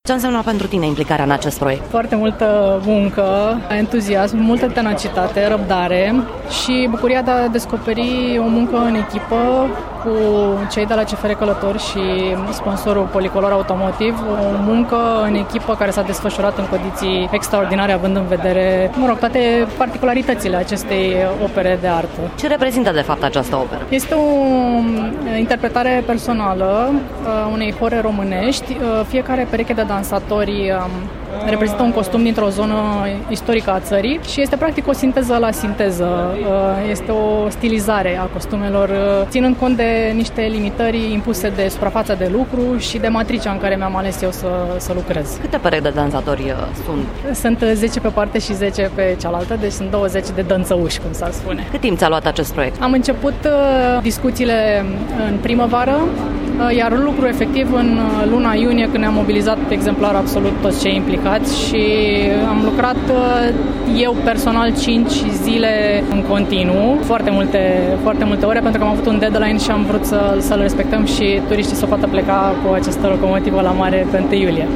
interviu-artista-1-iul.mp3